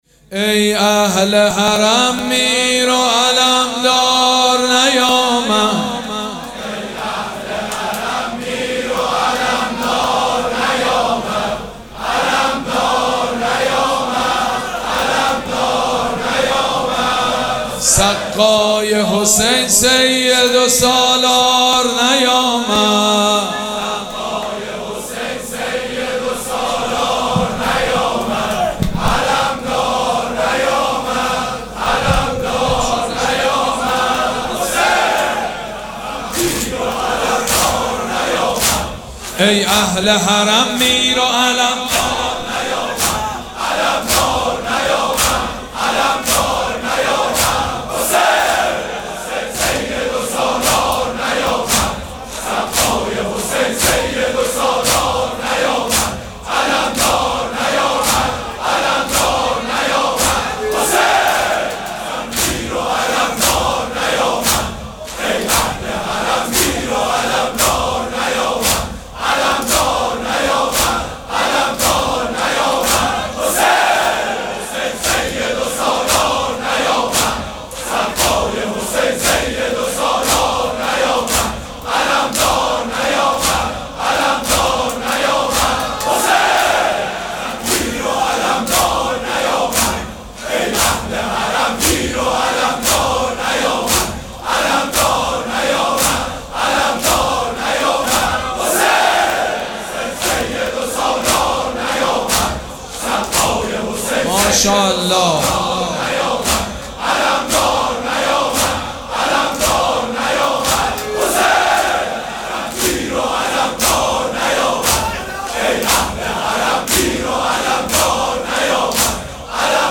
مراسم عزاداری شب نهم محرم الحرام ۱۴۴۷
دودمه
حاج سید مجید بنی فاطمه